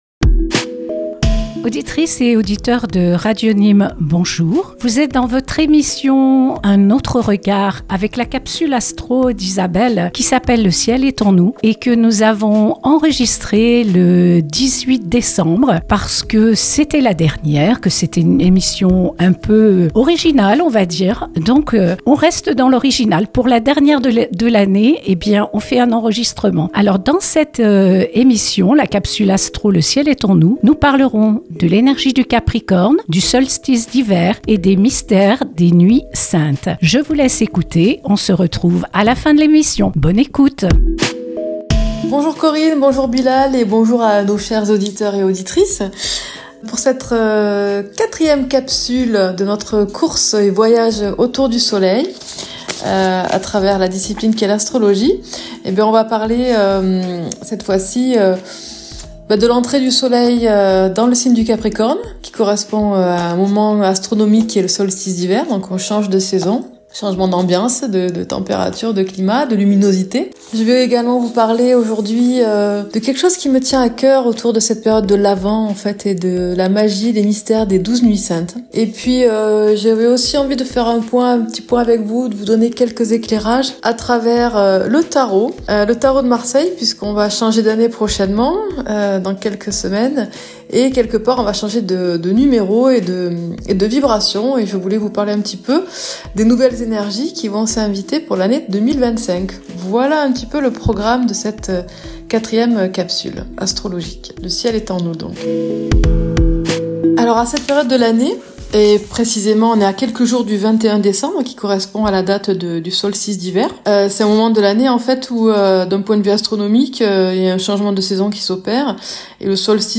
une émission enregistrée dans les studios de Radio Nîmes